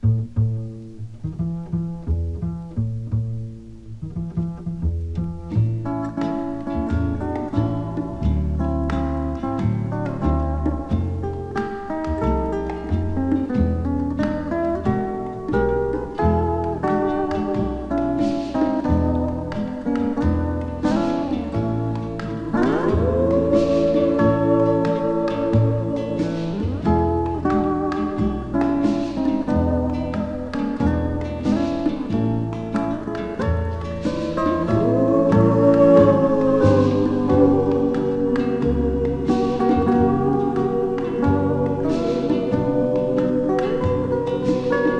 World, Exotic, Hawaii　UK　12inchレコード　33rpm　Stereo